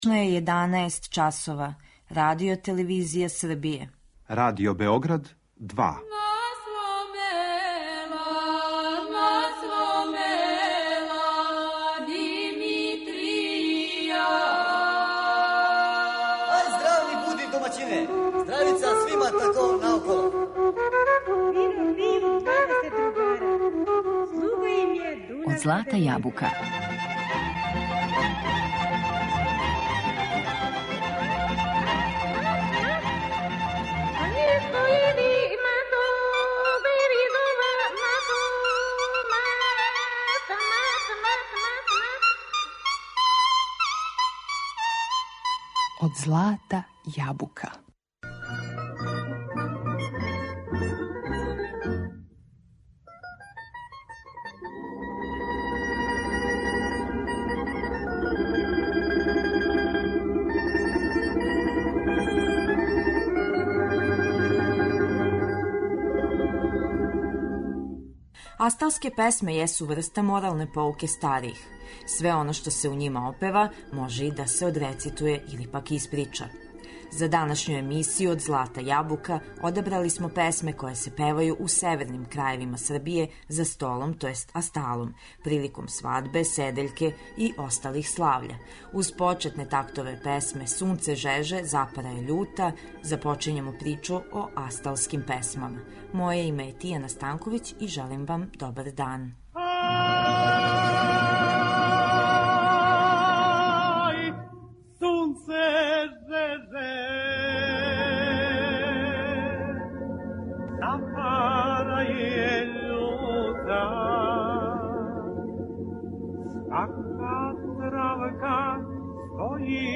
Асталске песме
Асталске песме су се изводиле на свадбама, седељкама и осталим весељима. Најчешће су се изводиле самостално тј. солистички, а данас се могу чути и у вокално-инструменталном облику или чак двогласно, па и вишегласно.